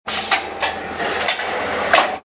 ※稼働しているのは日本に1台だけの長円形7色自動印刷機です
machine-sound.mp3